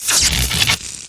PLAY vv_electricity
Play, download and share electricity original sound button!!!!
electricity_us849kj.mp3